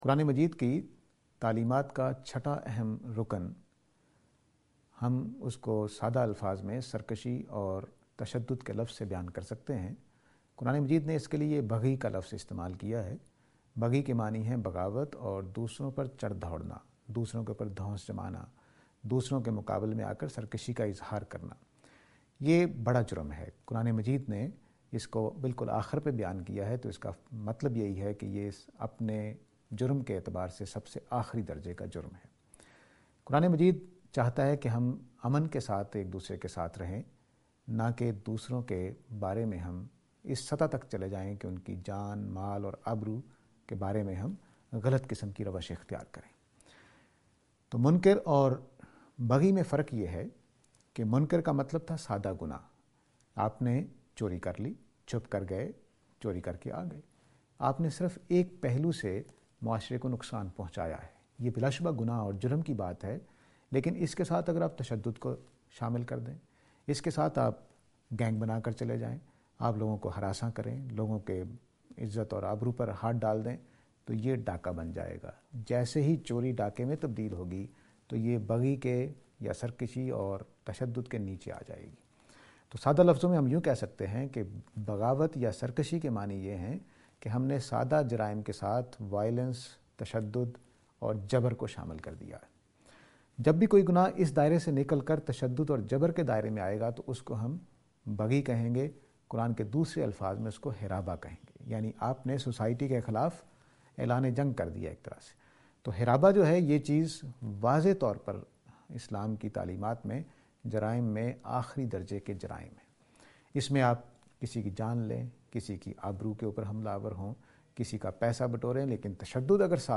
This lecture is and attempt to answer the question "Insurrection and violence".